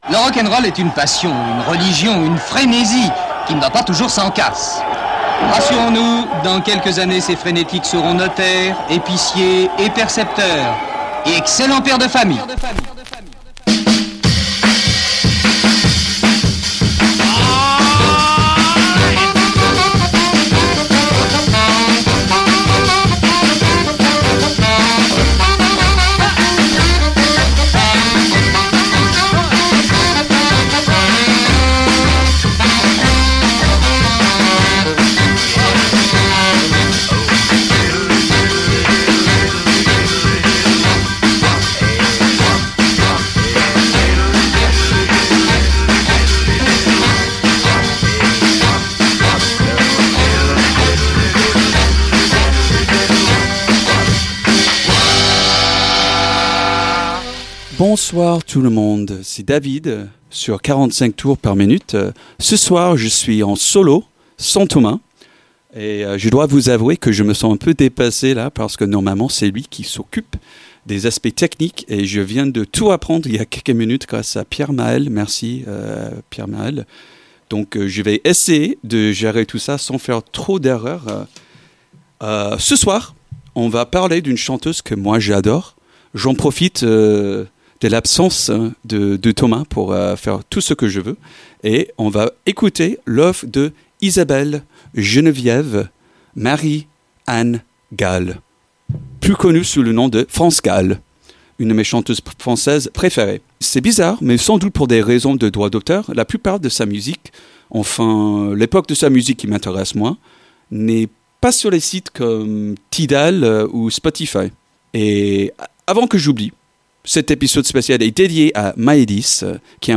passer des disques d'une de mes chanteuses préférées